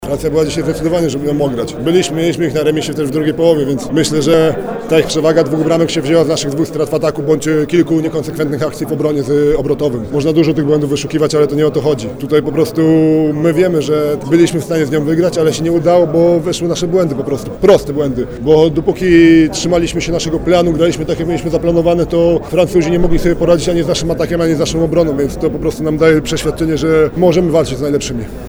Mówi Tomasz Gębala, rozgrywający „biało-czerwonych”: